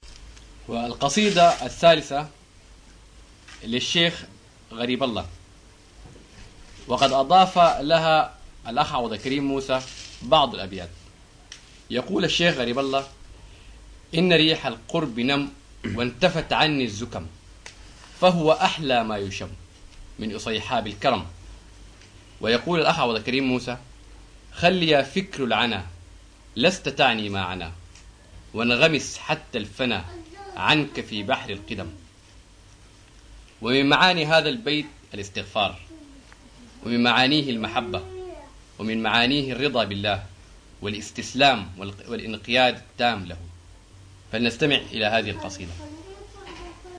القصائد العرفانية